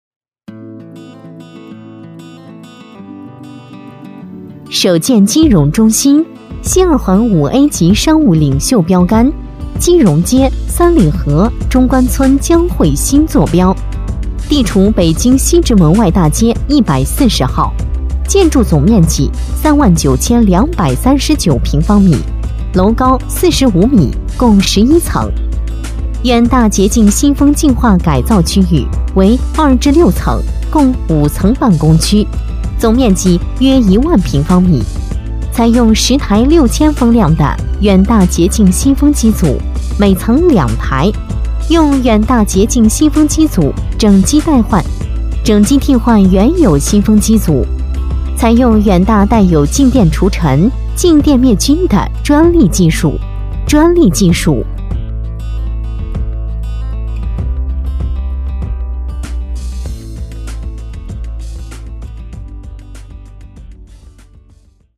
配音风格： 成熟 大气 活力 磁性 浑厚 稳重